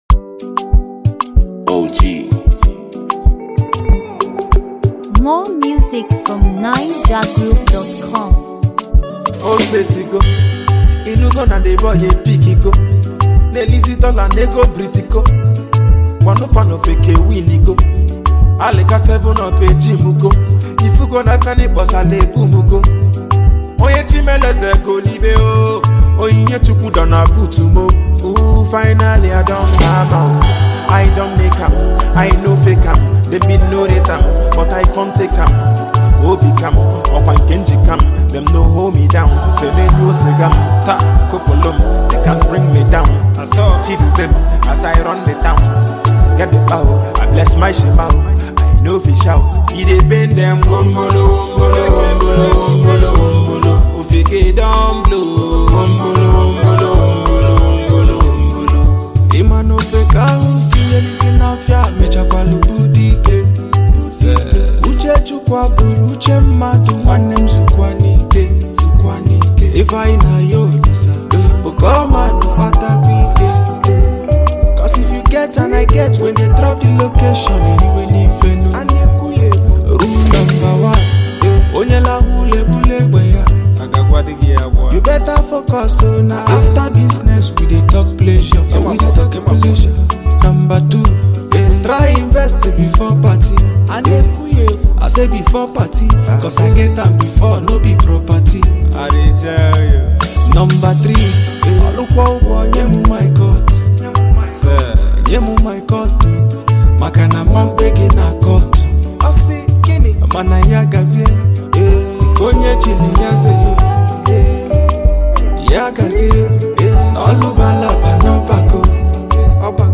Latest, Naija-music
melodic delivery